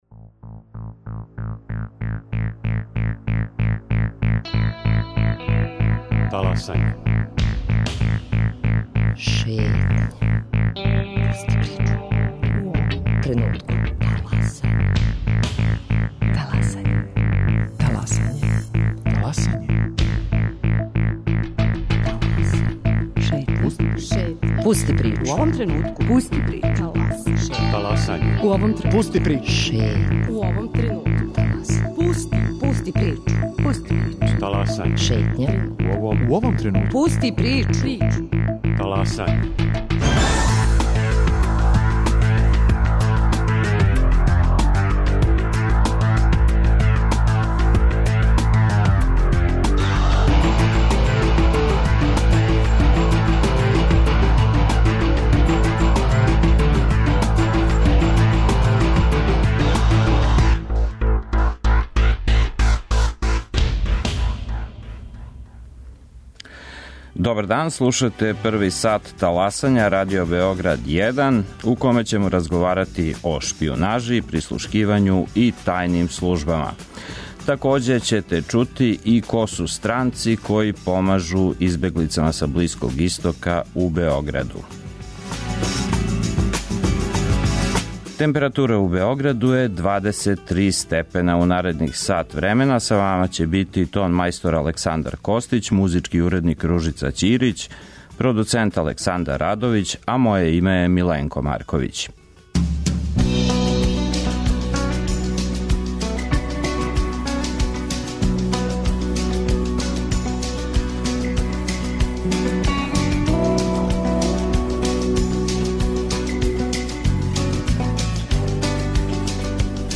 За Шетњу говоре људи из Пакистана, Бразила, Сједињених држава, Шведске и Јордана.